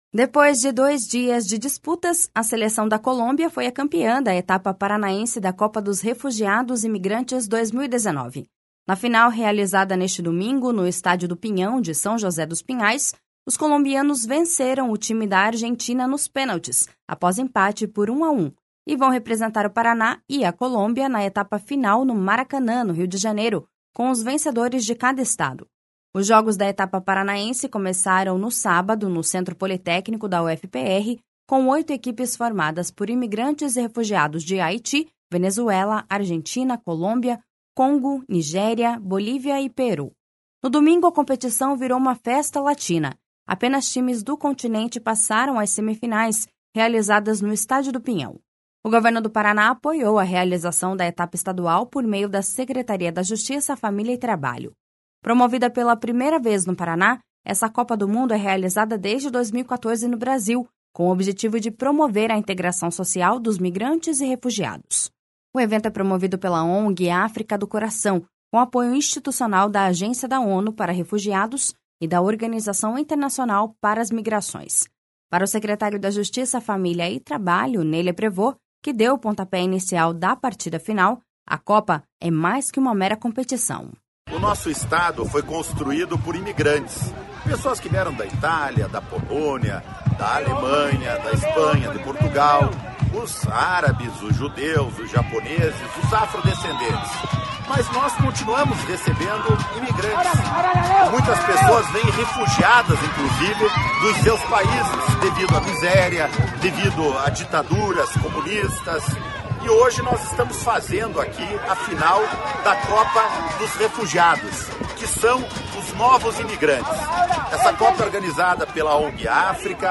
Para o secretário da Justiça, Família e Trabalho, Ney Leprevost, que deu o pontapé inicial da partida final, a Copa é mais que uma mera competição.// SONORA NEY LEPREVOST.//